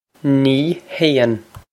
Pronunciation for how to say
Nee hayn.
This is an approximate phonetic pronunciation of the phrase.